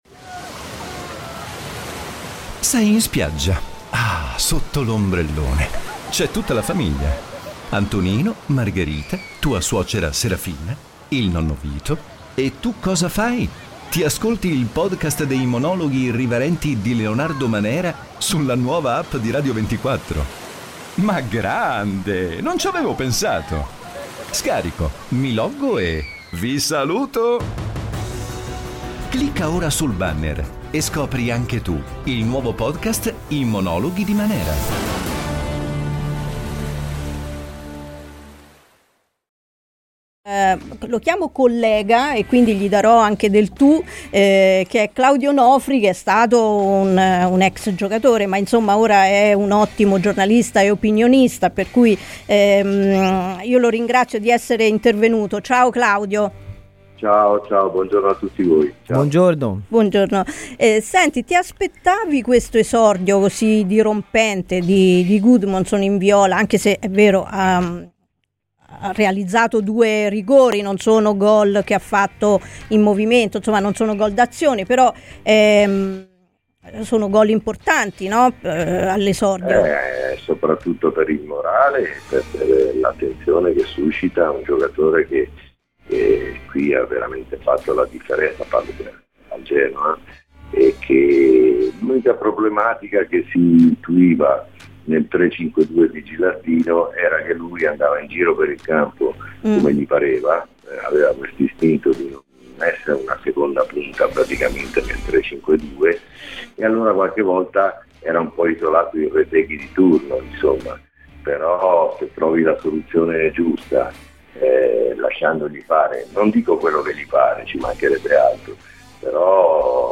ASCOLTA IL PODCAST PER L'INTERVISTA COMPLETA Claudio Onofri a Radio FirenzeViola